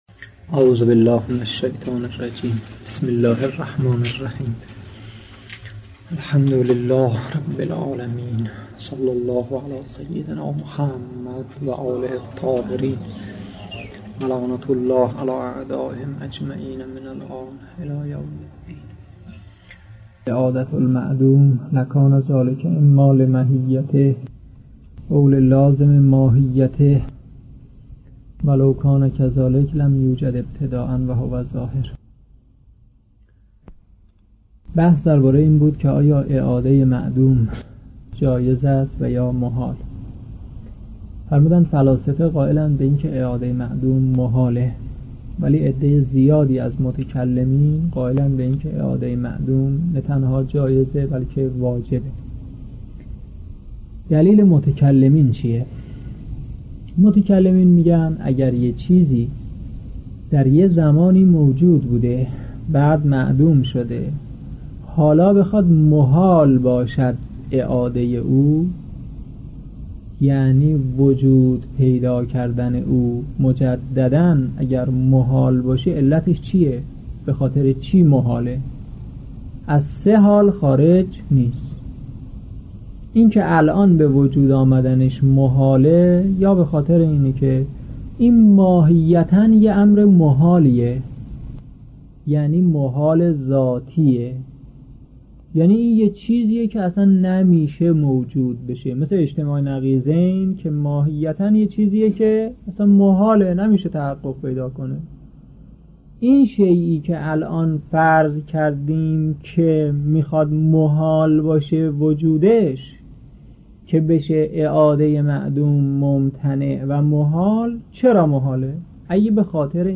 در این بخش، کتاب «بدایة الحکمة» که می‌توان دومین کتاب در مرحلۀ شناخت علم فلسفه دانست، به صورت ترتیب مباحث کتاب، تدریس می‌شود.